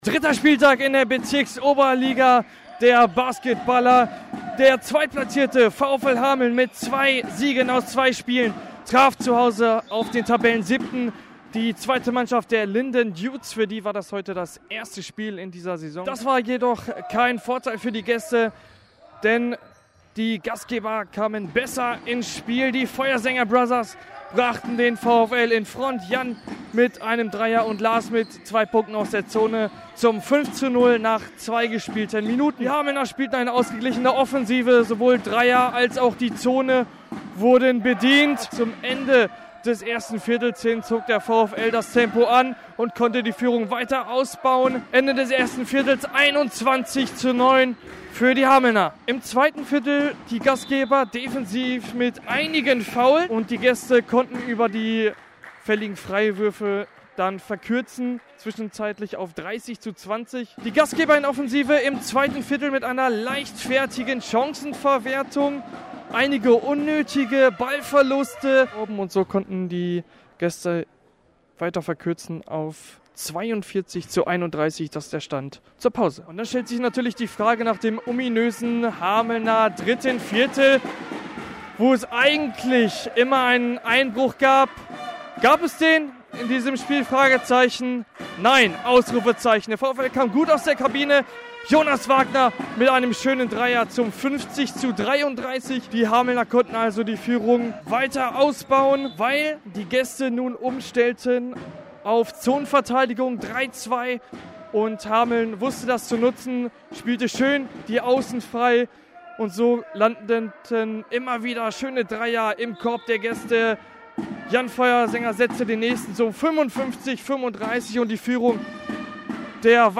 Reportage und Interview